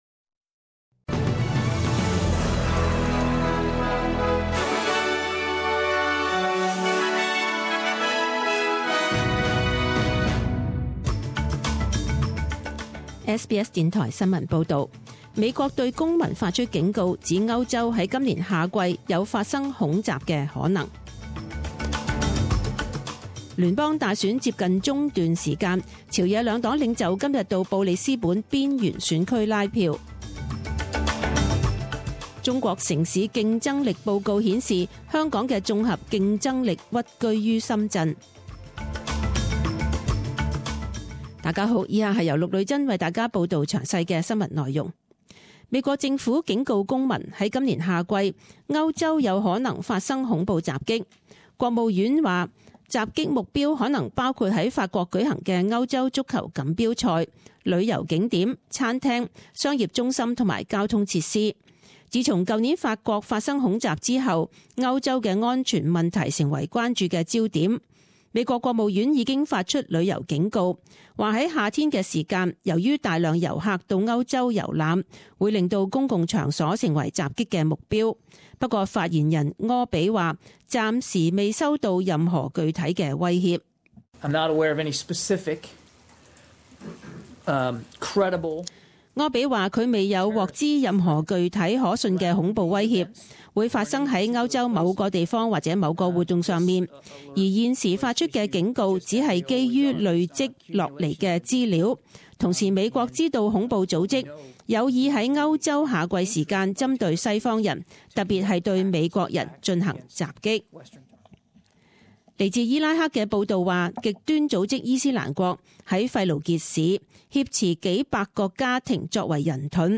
Details News Bulletins